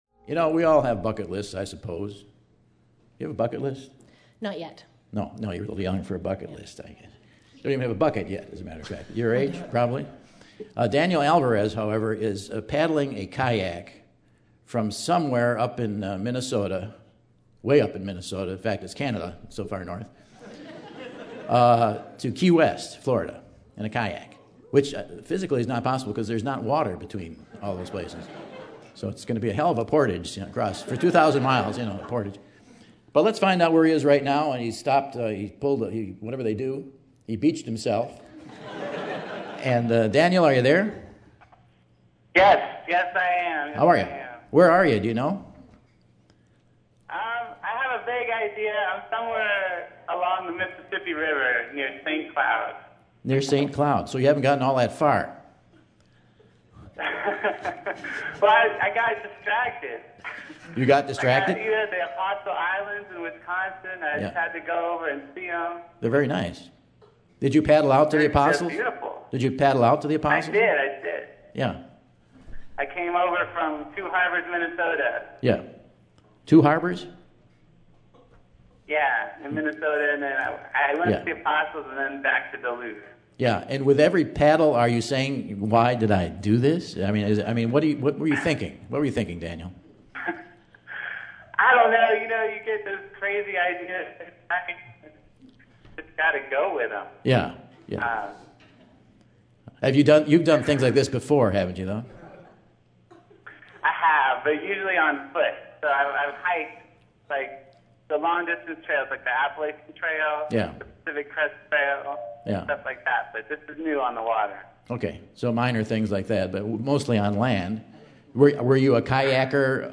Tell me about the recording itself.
September 22, 2012 - Madison, WI - Monona Terrace | Whad'ya Know?